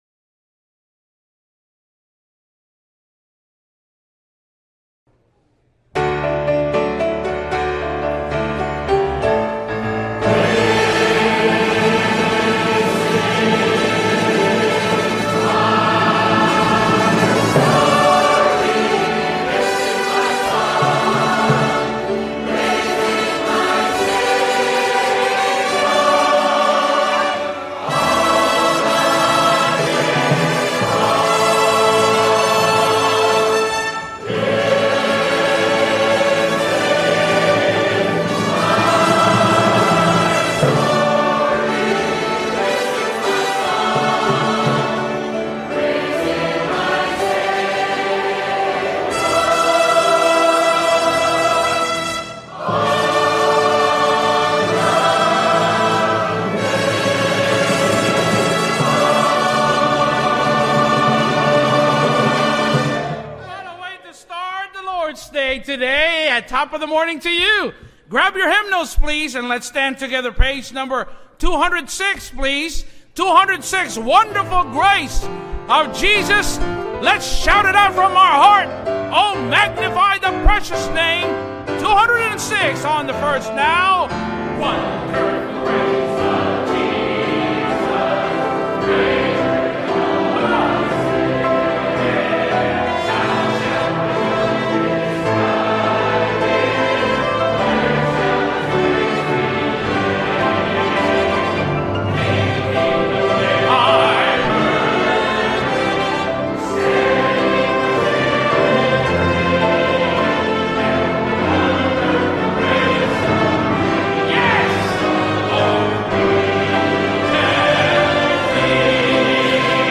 Live Sunday Morning Service